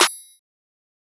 Snr (Tarentino).wav